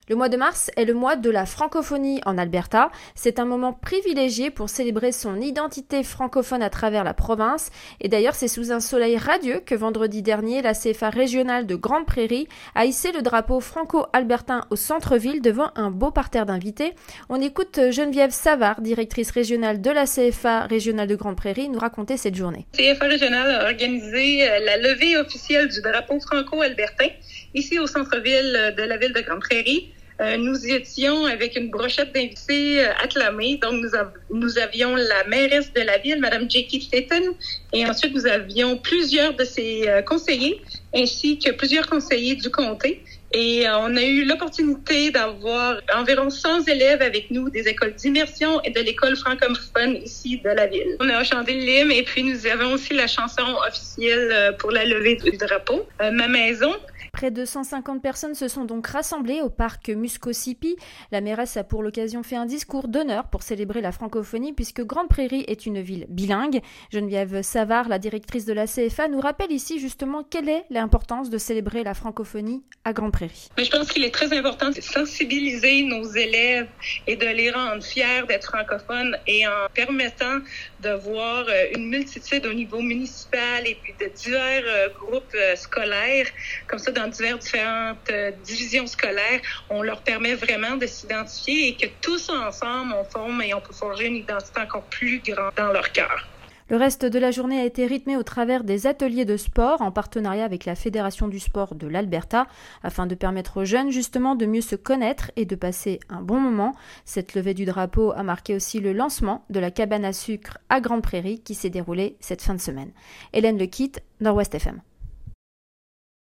Un reportage de notre journaliste